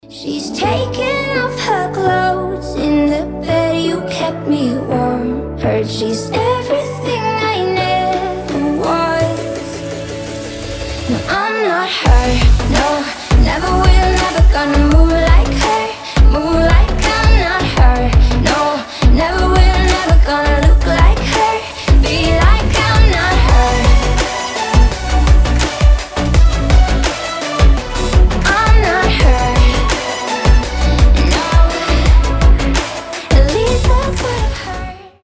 • Качество: 320, Stereo
поп
женский вокал
dance
Electronic
красивый женский голос